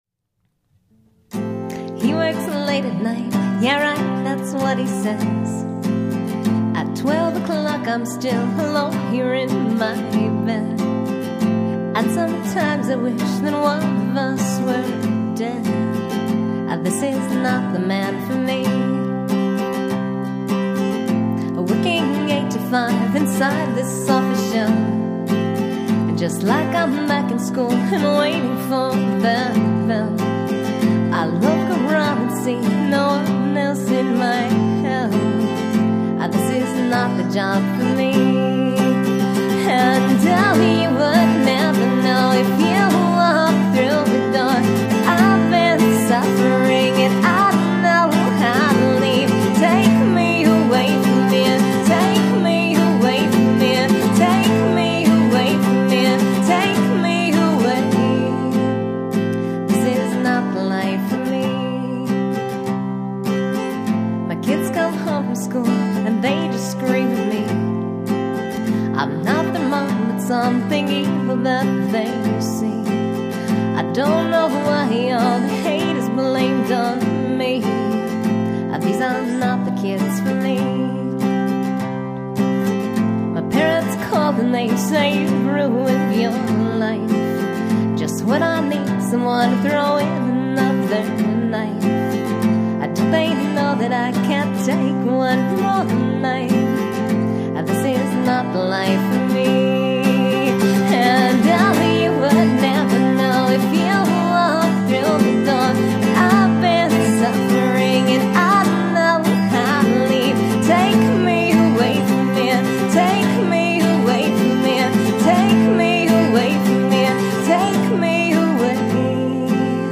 64kbps mono MP3
guitar and vocals